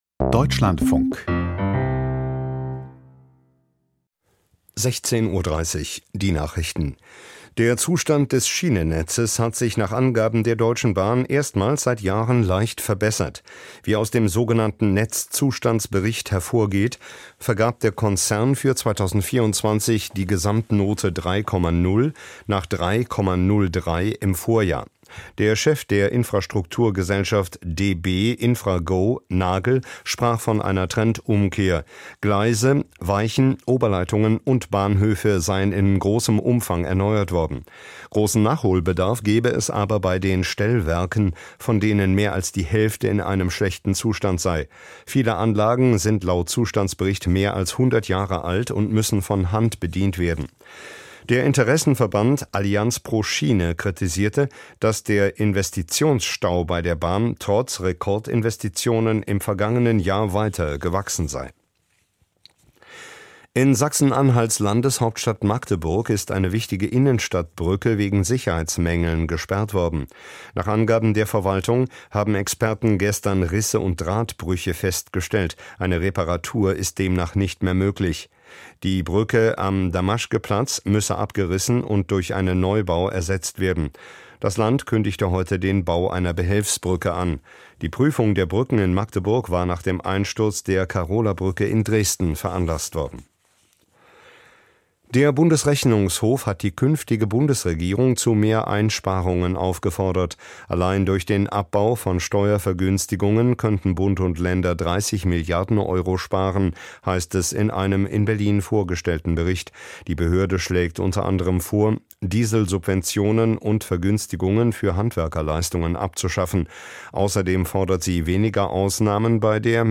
Die Deutschlandfunk-Nachrichten vom 15.04.2025, 16:30 Uhr